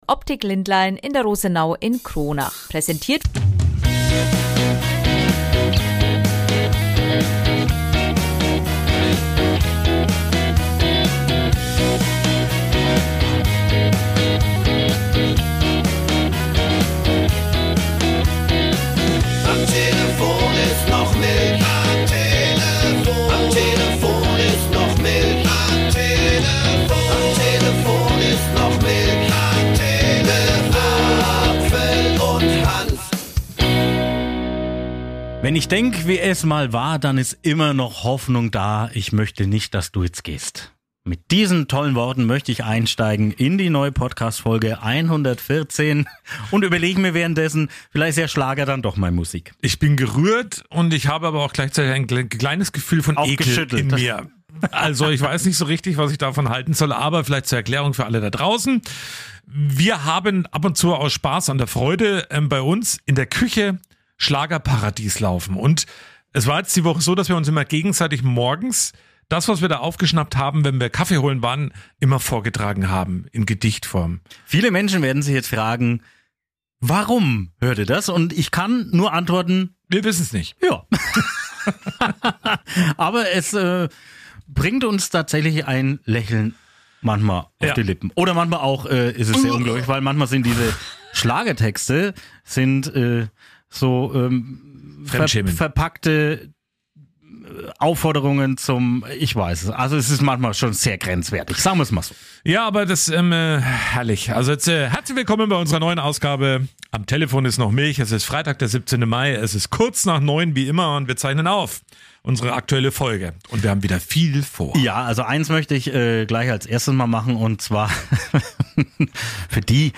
Dazu gibt es viele Berichte und Interviews